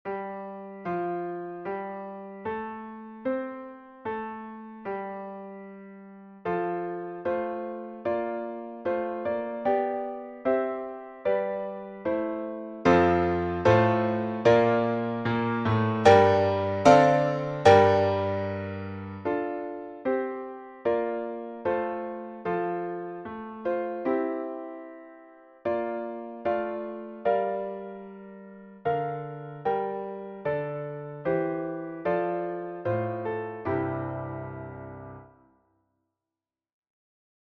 Japanische Hymne.
japanische_hymne.mp3